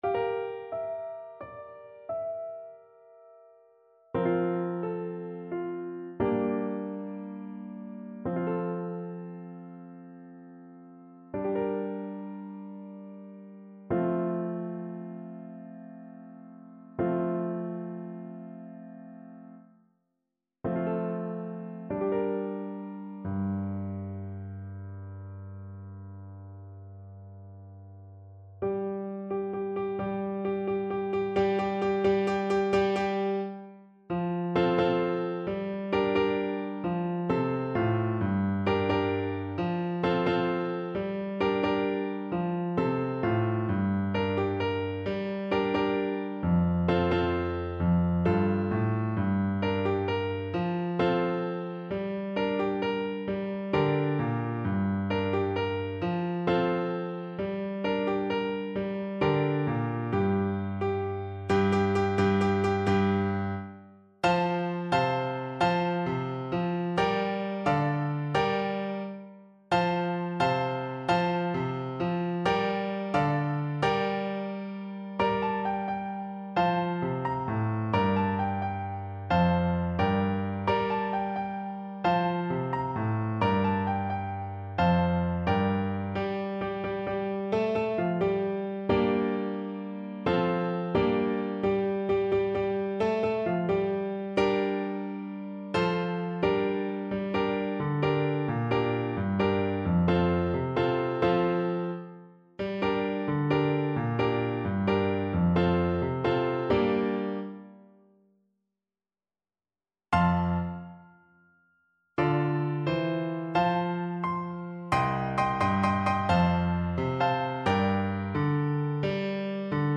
French Horn
6/8 (View more 6/8 Music)
E4-F5
G minor (Sounding Pitch) D minor (French Horn in F) (View more G minor Music for French Horn )
Slow .=c.80
Traditional (View more Traditional French Horn Music)